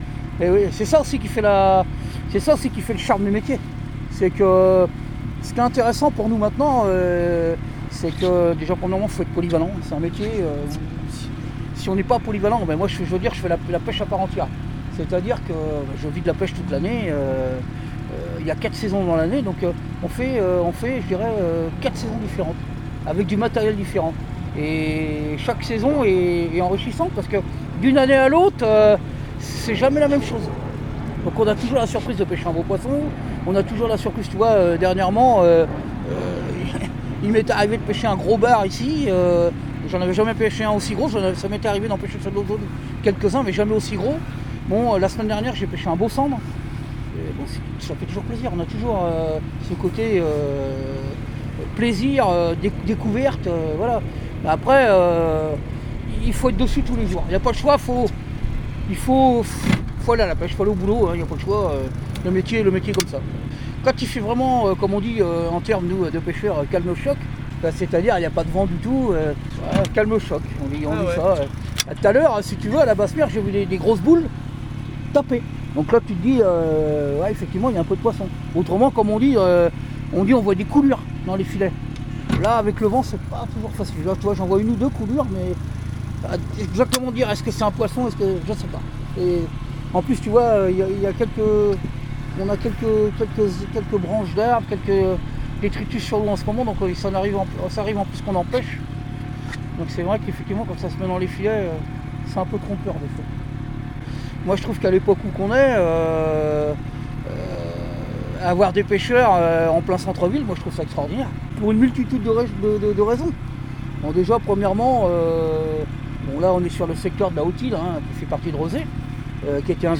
Une pêche aux sons